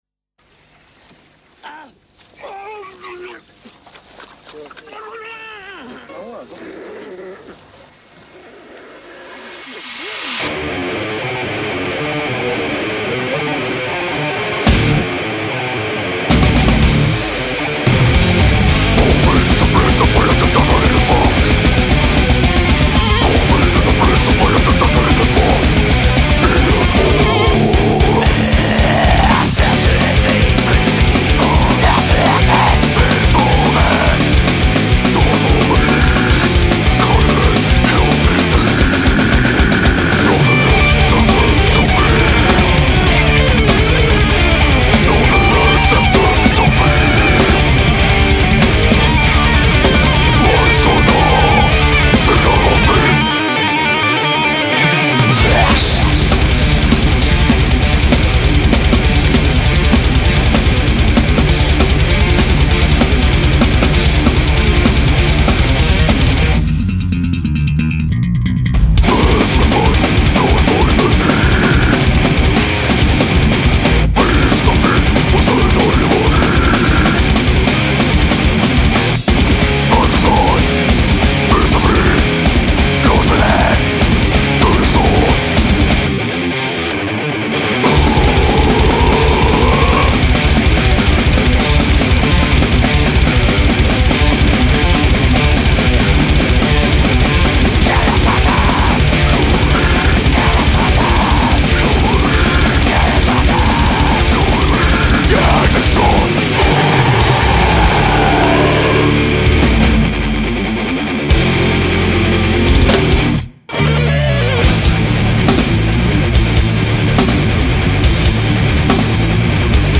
mélange de riffs acérés et mélodies pénétrantes